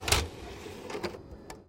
Звуки игровой консоли
Звук вставки игрового диска в консоль Nintendo